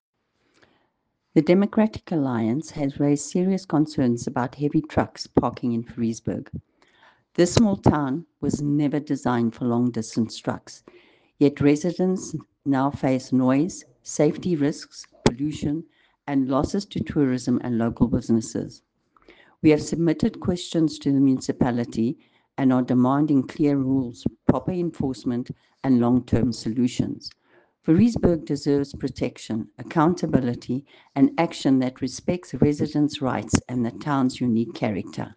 Afrikaans soundbites by Cllr Irene Rügheimer and